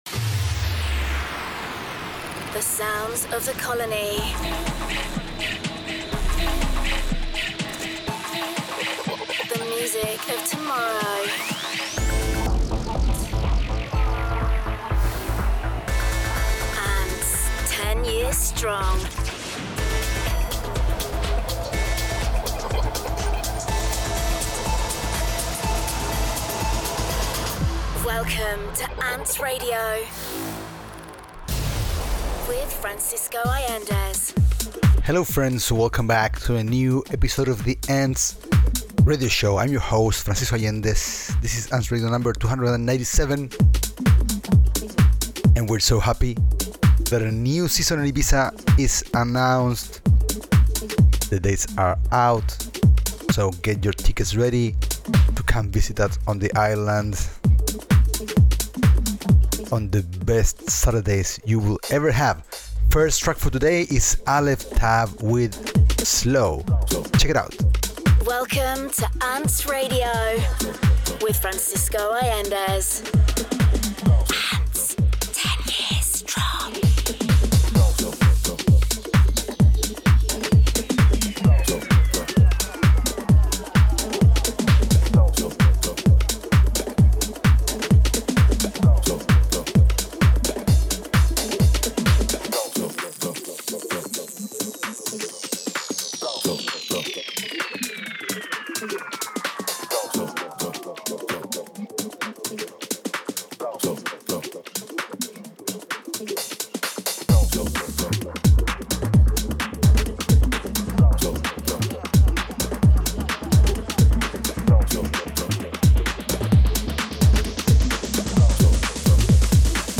Also find other EDM Livesets, DJ Mixes and Radio Show